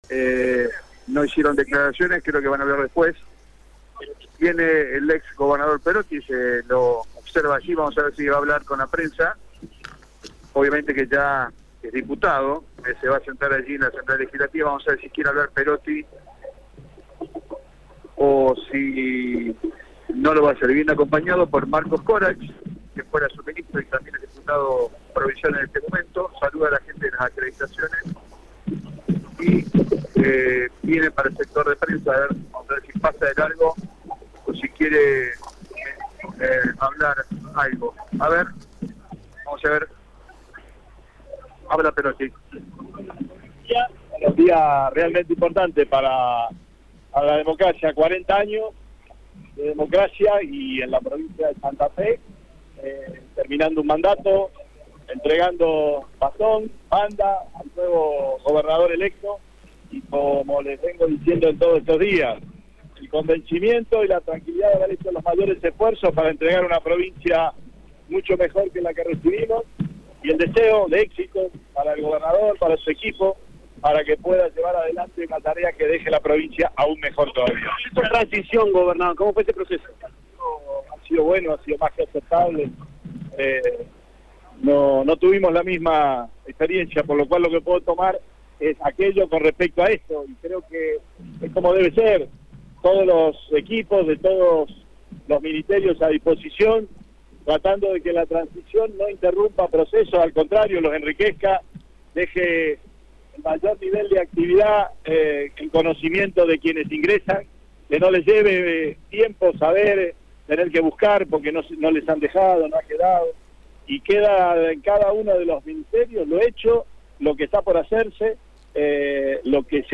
En el ingreso a la Legislatura santafesina, Radio EME está presente con su móvil para tomar contacto con los principales políticos en la asunción de Maximiliano Pullaro como Gobernador de la Provincia de Santa Fe.
Escucha la palabra de Omar Perotti en Radio EME: